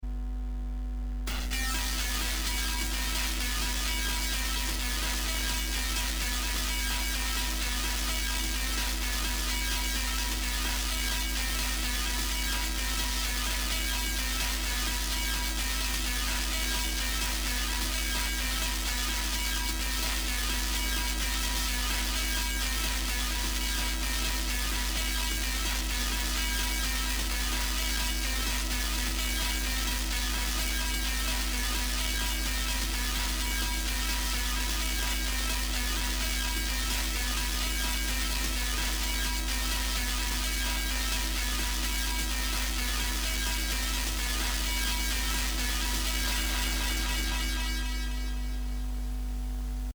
captured an encoder shaft shearing off…!?
this content captures a man refusing to accept this just happened, reinsert, realise it still fits in the groove of the snap and can control, but is no longer attached…
cutters choice wheeze laugh reaction bonus at end.